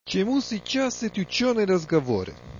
Festa di fine corsi
In occasione della classica festa di conclusione dell' anno sociale, ben due allestimenti "plen air" nel cortile di Palazzo Rinuccini: